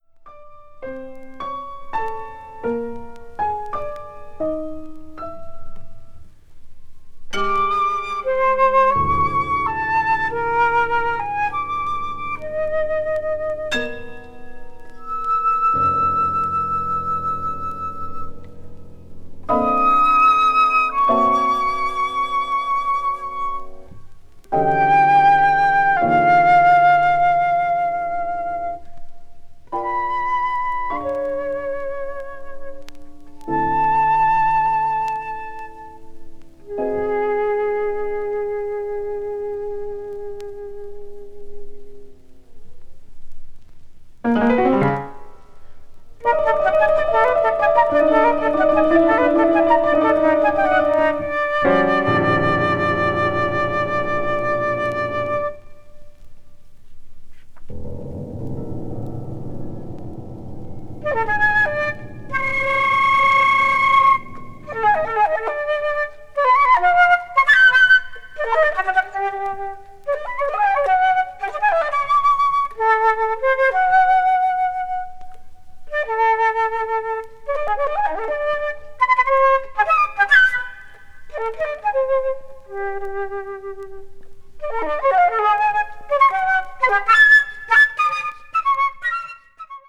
media : VG+/VG+(薄いスリキズによる軽いチリノイズが入る箇所あり)
スタジオのルーム・エコーも一切なく、フルートとピアノの非常に生々しい音が響いています。